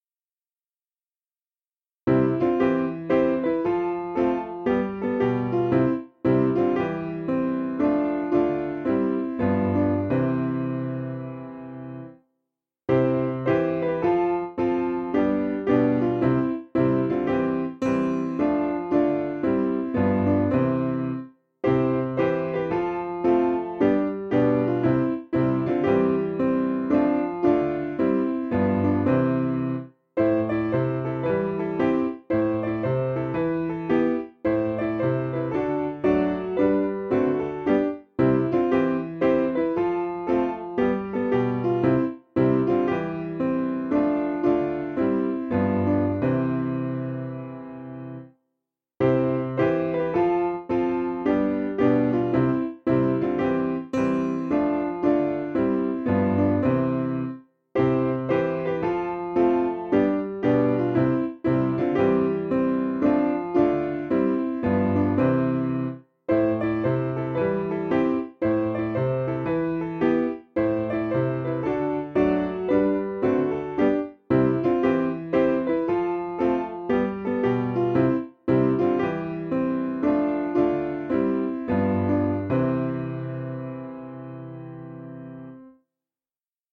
Key: C Major
English Traditional Melody